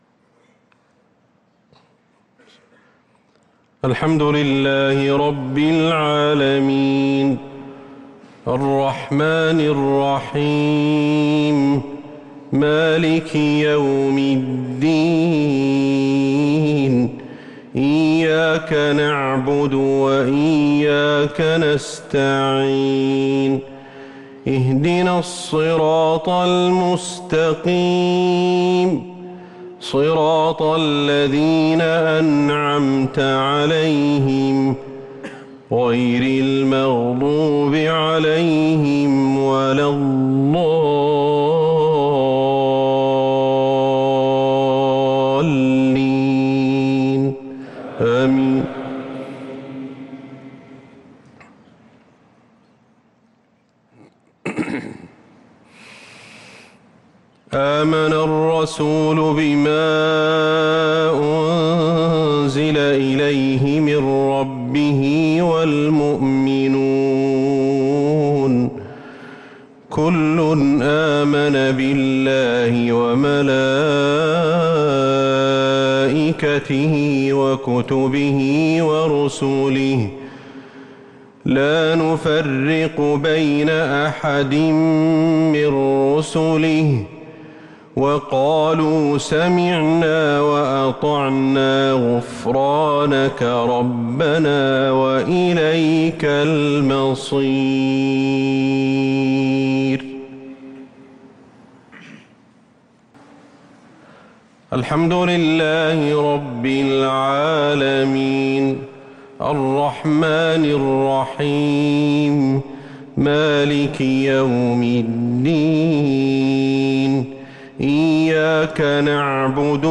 صلاة العشاء للقارئ أحمد الحذيفي 29 رمضان 1443 هـ
تِلَاوَات الْحَرَمَيْن .